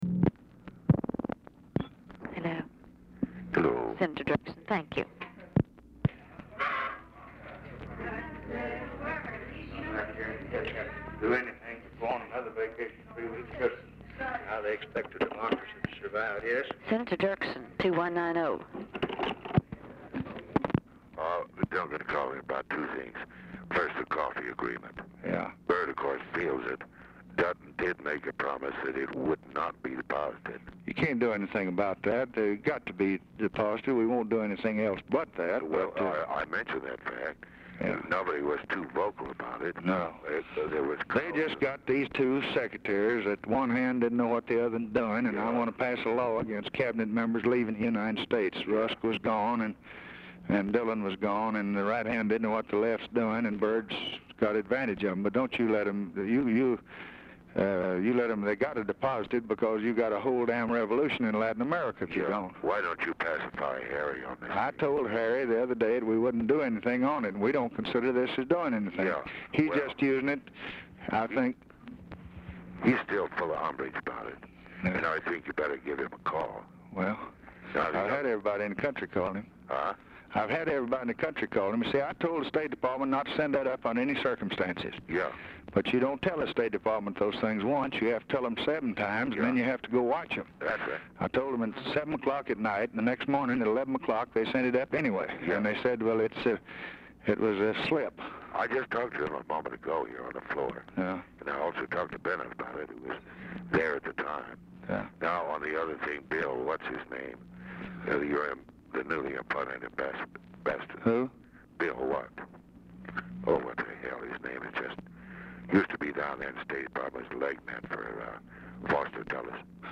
Telephone conversation # 574, sound recording, LBJ and EVERETT DIRKSEN
0:20 OFFICE CONVERSATION PRECEDES CALL
Format Dictation belt
Oval Office or unknown location